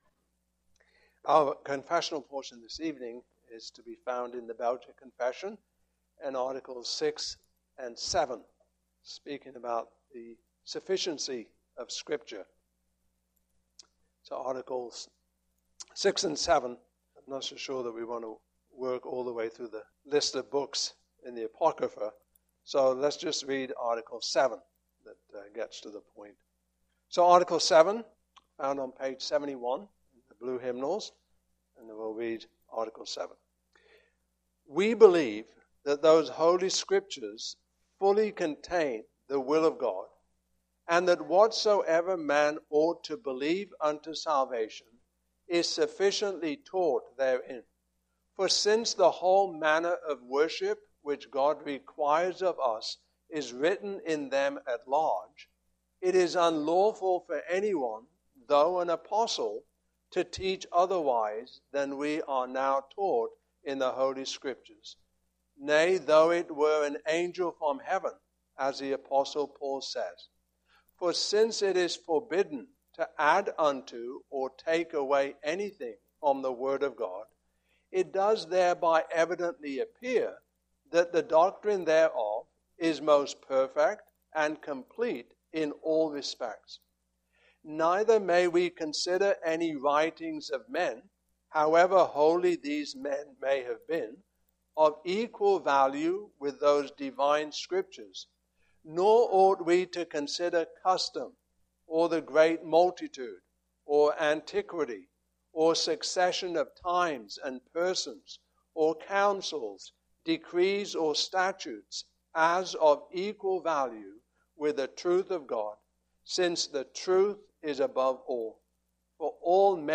Service Type: Evening Service Topics: Articles 6-7